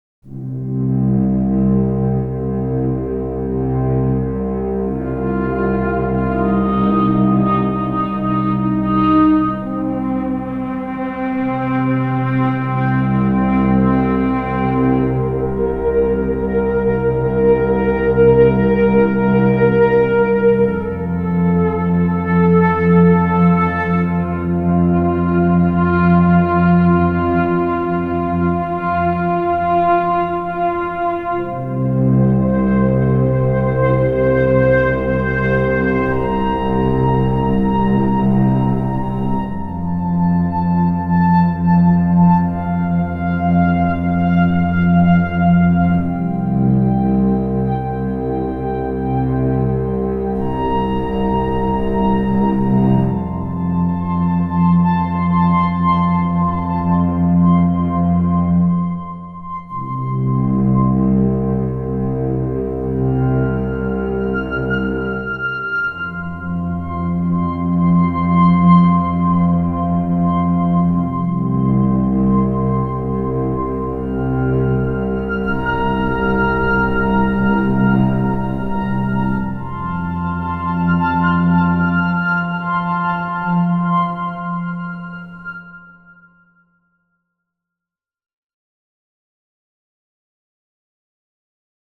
This meticulous process transformed the strings into a synth-like texture while preserving their organic richness creating something truly distinctive: a synth made from strings.
RS MIX – the re-amped string synth
Sul Tasto RS MIX (string synth) Violin + Cello
sul-tasto-rs-mix-tech-demo.wav